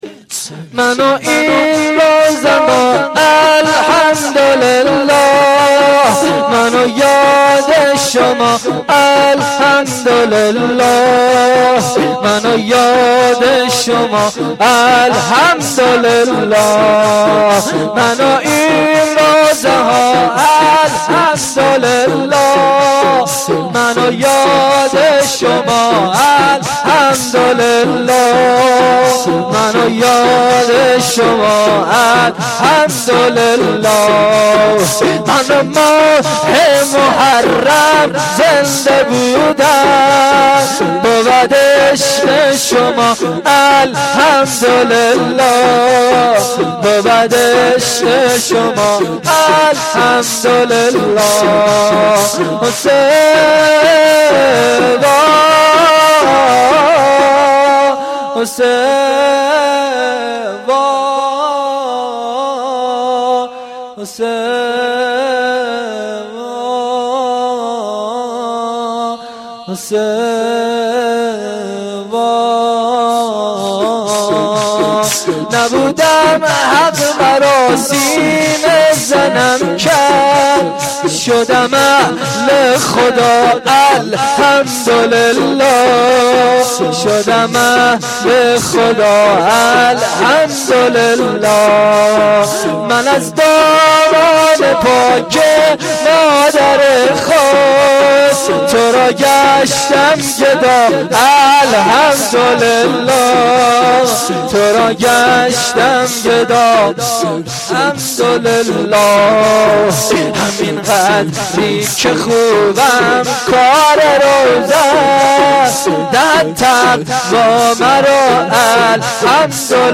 گزارش صوتی جلسه رحلت امام
روضه